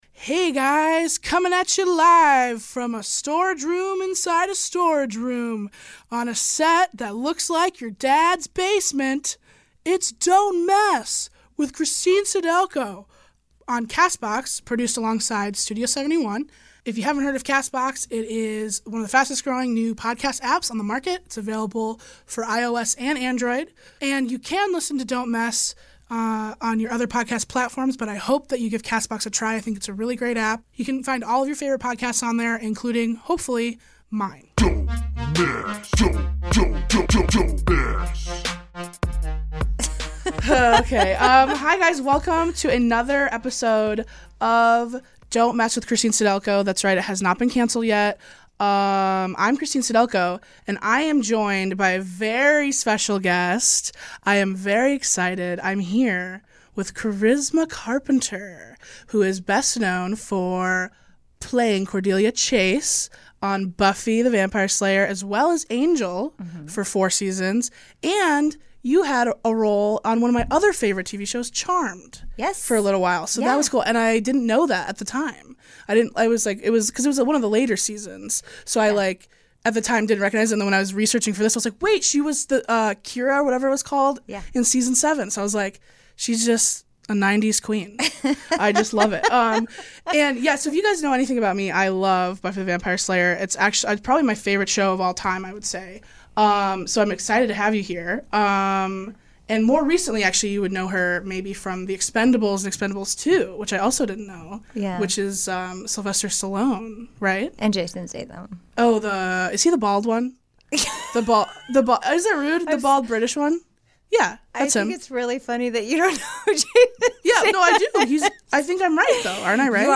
Christine is joined in the studio by actor Charisma Carpenter, best known for her role as Cordelia Chase on TV series "Buffy the Vampire Slayer" and its spin-off series "Angel". They discuss Buffy, Buffy, and more Buffy.